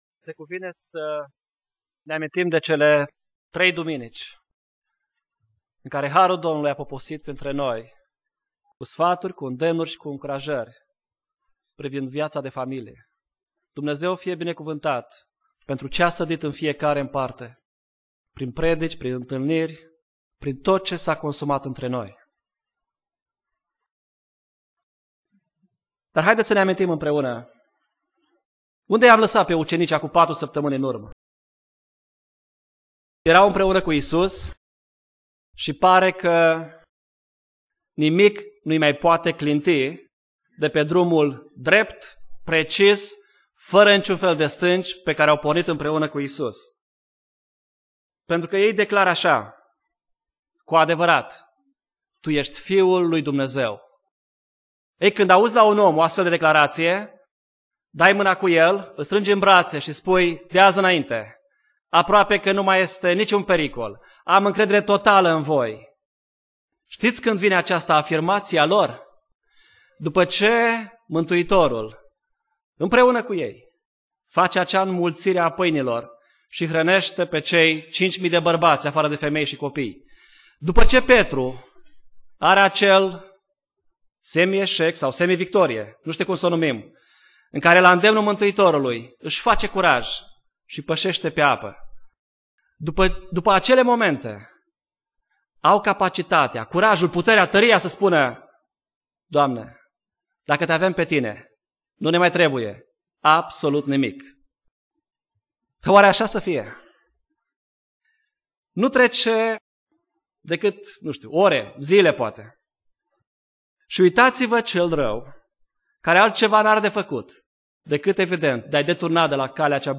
Predica Exegeza - Matei 15